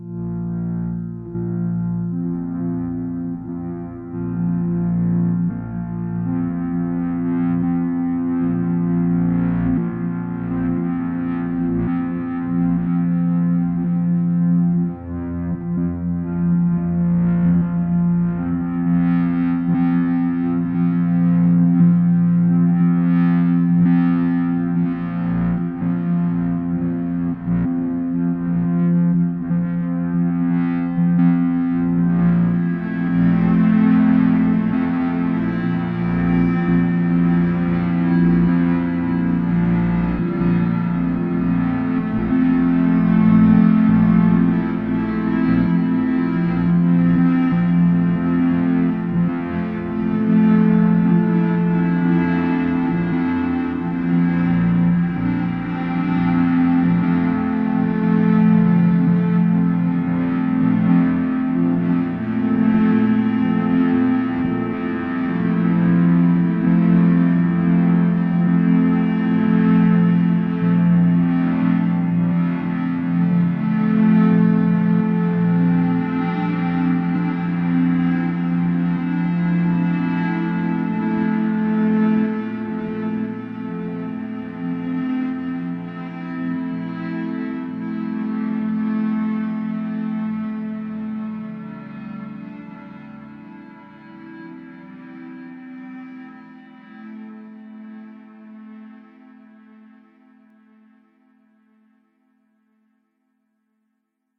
Made using the pulse of PSR B0329+54 as a metronome.